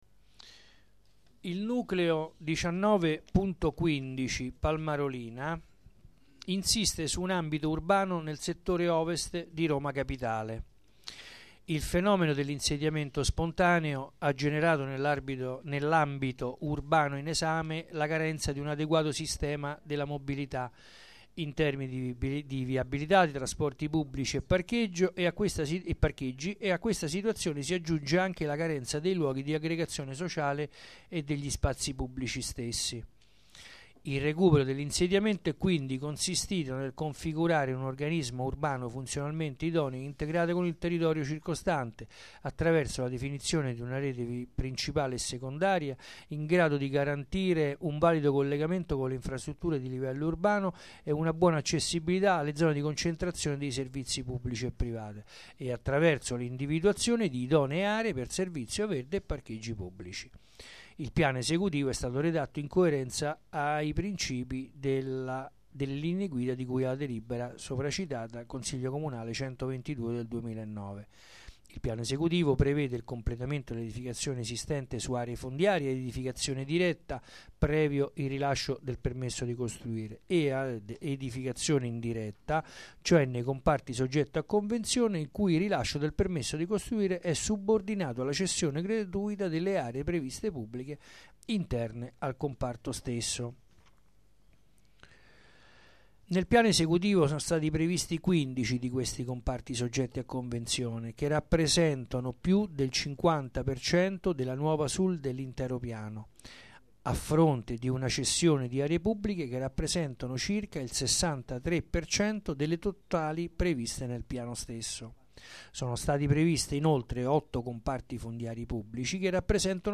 Assemblea
Registrazione integrale dell'incontro svoltosi il 3 aprile 2013 presso la sala consiliare del Municipio Roma XIX